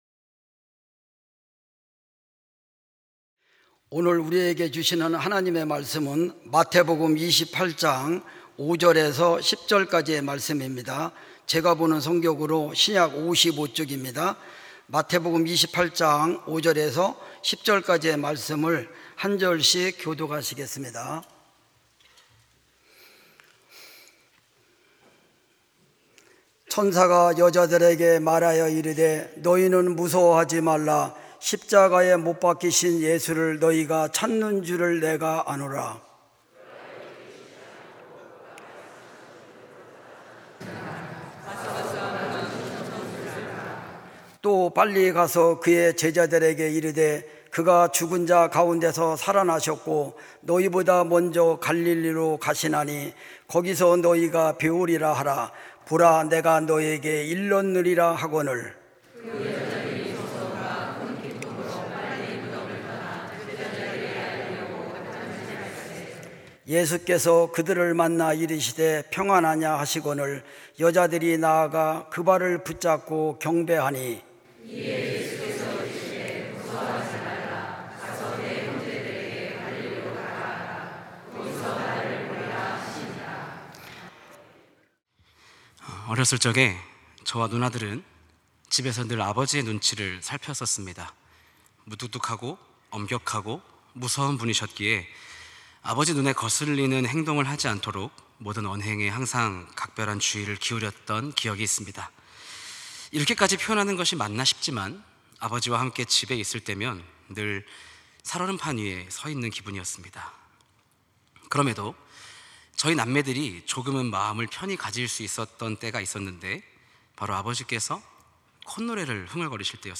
찬양예배 - 무서움과 큰 기쁨으로